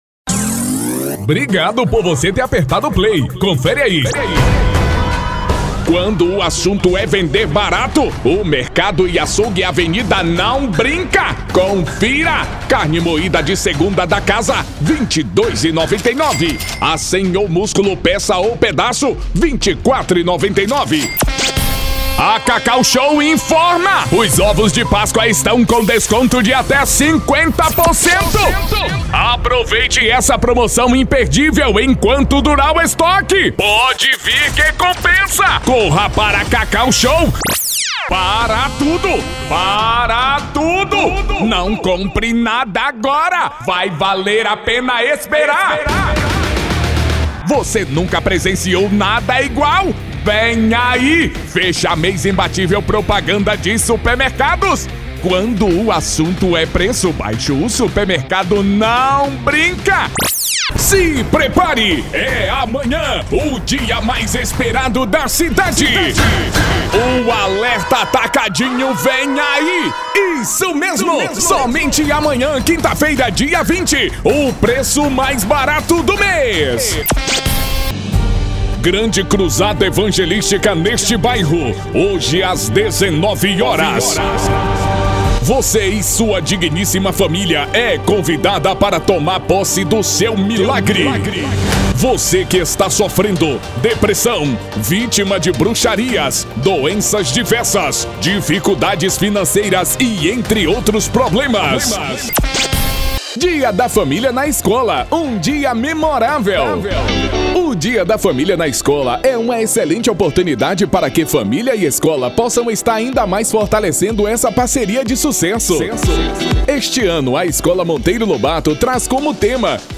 IMPACTO, JOVEM ,VAREJO,ANIMADO, CARRO DE SOM PORTA DE LOJA ,RICARDO ELETRO,PADRÃO,: